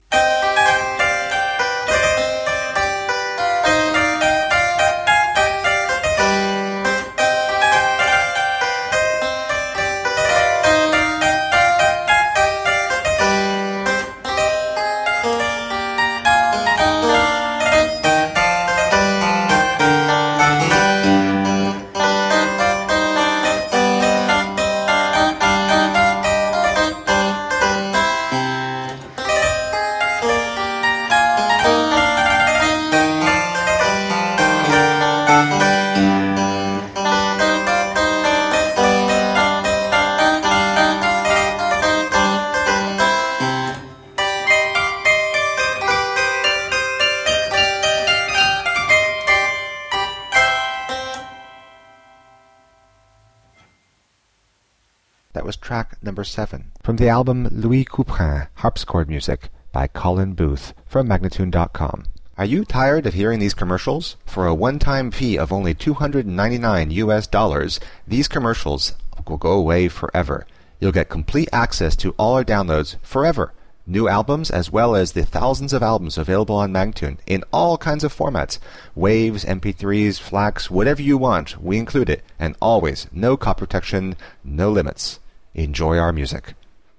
Solo harpsichord music.
played on a wonderful original French harpsichord of 1661